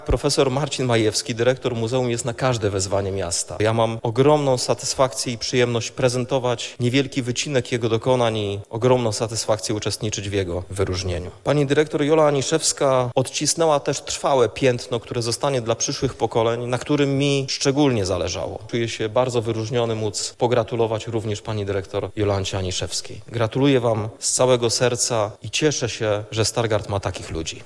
Prezentacji dokonań stargardzkich zasłużonych dokonał Prezydent Rafał Zając.
mówi Prezydent Stargardu Rafał Zając.